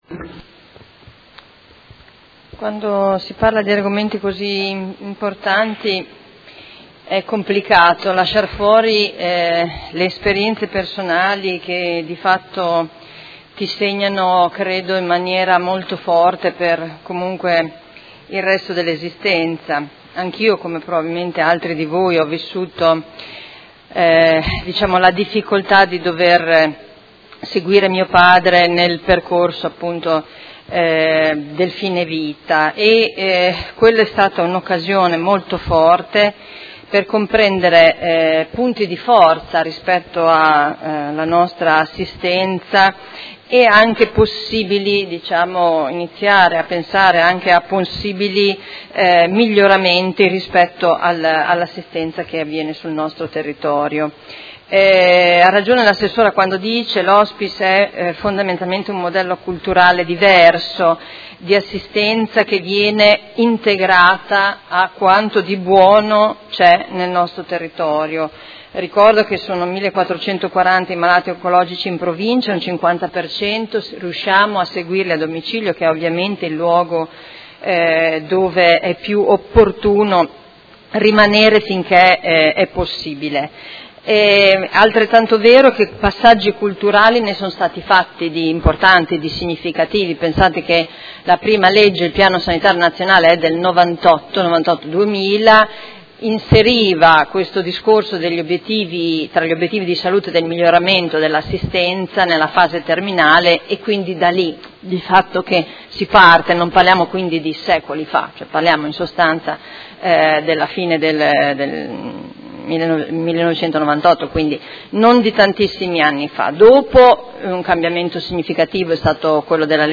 Seduta del 04/06/2018.